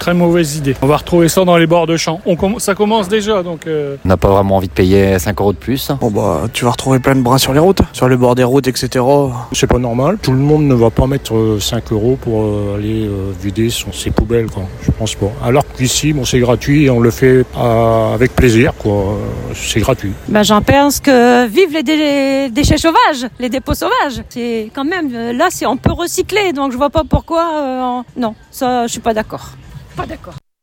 De quoi faire bondir les utilisateurs qui craignent des dépôts sauvages et ne comprennent pas cette éventuelle mesure .Nous sommes allés à votre rencontre LOGO HAUT PARLEUR réactions :